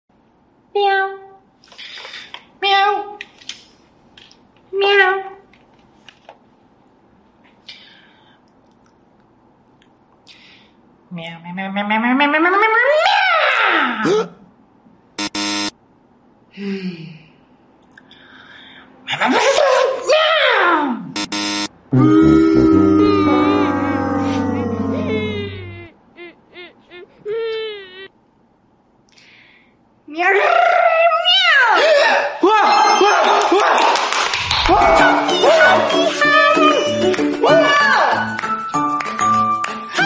cat sound challenge sound effects free download